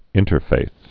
(ĭntər-fāth)